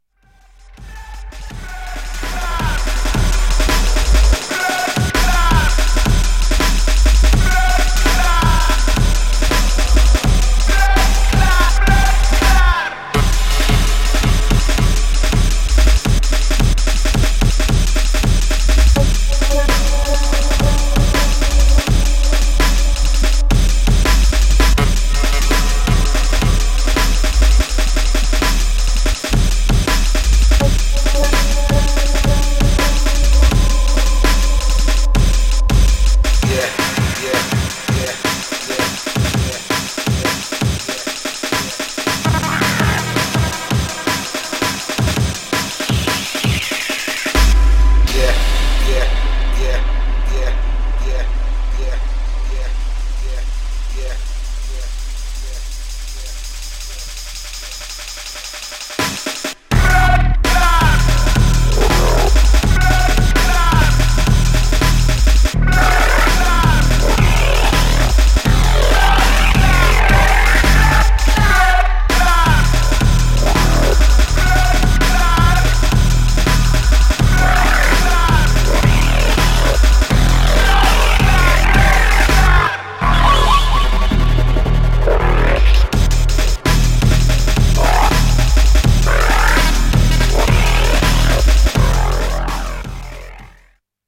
Styl: Dub/Dubstep, Drum'n'bass, Jungle/Ragga Jungle